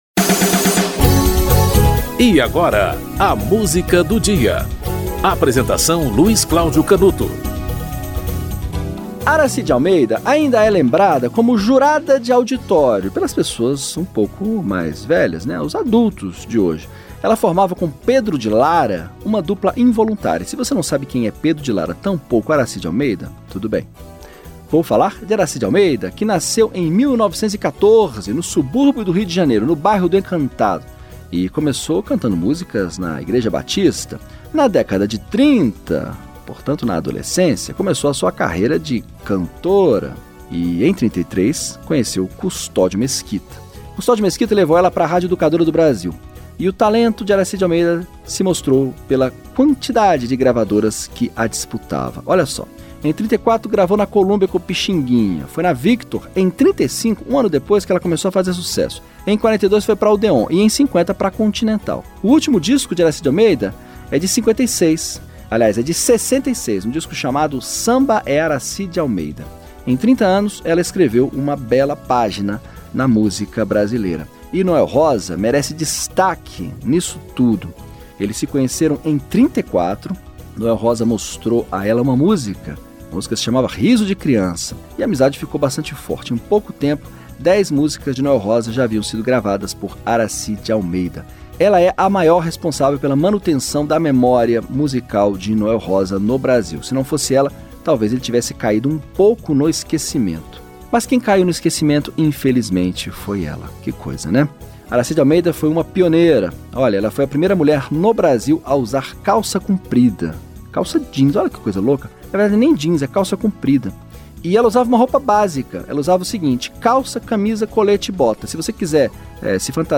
Produção e apresentação: